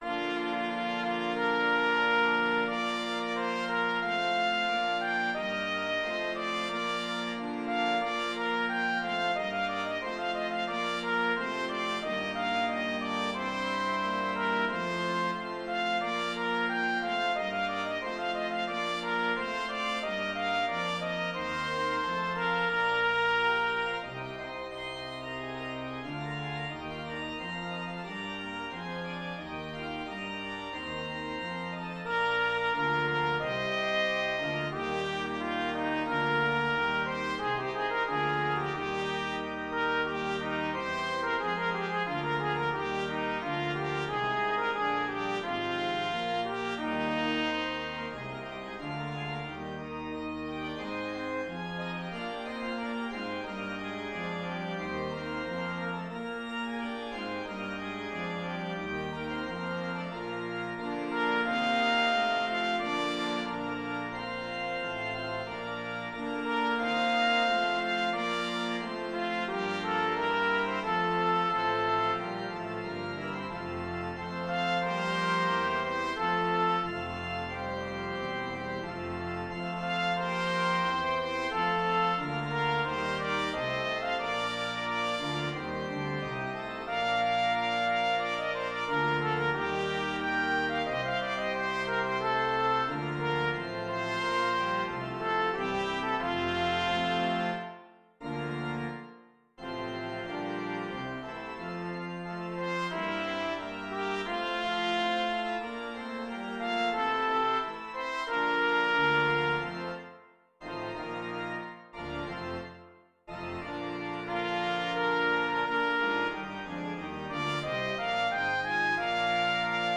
Classical Buxtehude, Dietrich Schaffe in mir, Gott, ein rein Herz, BuxWV 95 Trumpet version
Trumpet  (View more Intermediate Trumpet Music)
Classical (View more Classical Trumpet Music)